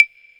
TI100CLAV1-L.wav